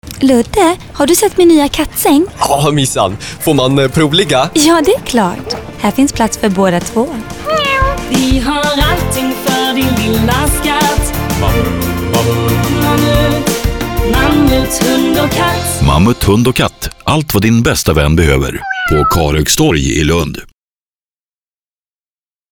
Kommerziell, Natürlich, Verspielt, Warm, Corporate
Kommerziell